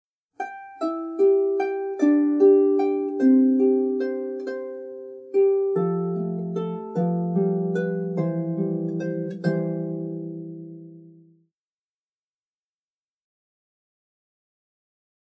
Genere: Children’s.